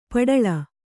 ♪ paḍaḷa